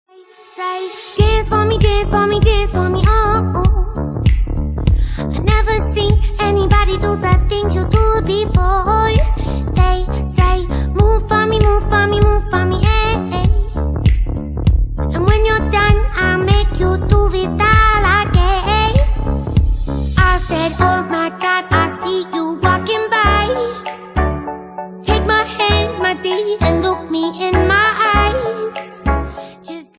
Ringtones Category: Pop - English